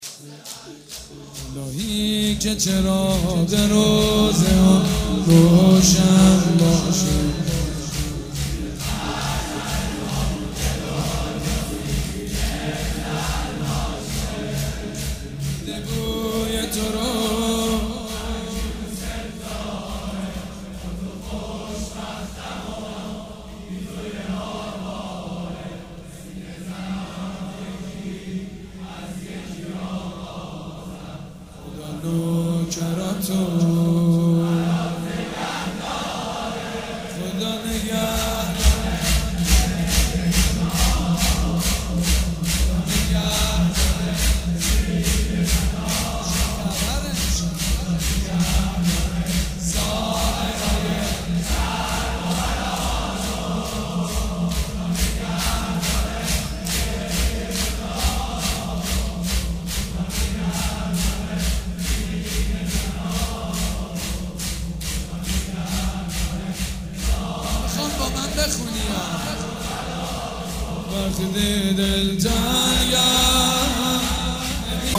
شب یازدهم محرم الحرام‌
شور
حاج سید مجید بنی فاطمه
مراسم عزاداری شب شام غریبان